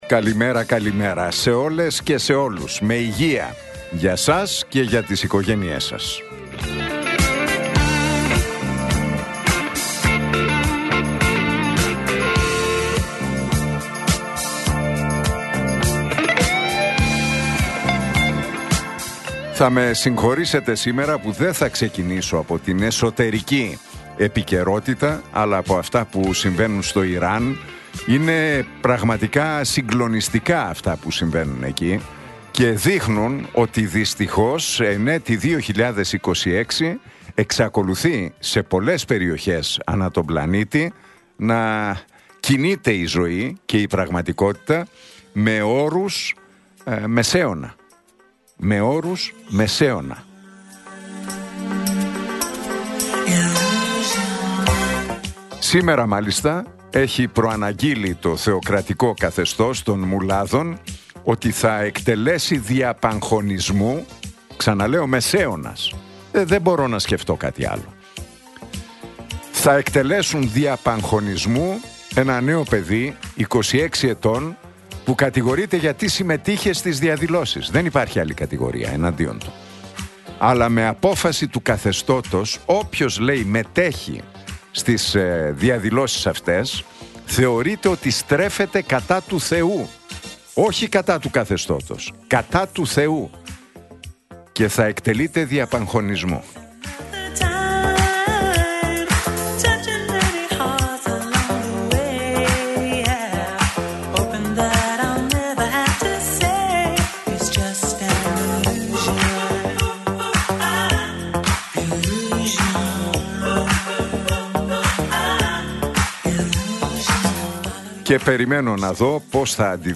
Ακούστε το σχόλιο του Νίκου Χατζηνικολάου στον ραδιοφωνικό σταθμό Realfm 97,8, την Τετάρτη 14 Ιανουαρίου 2026.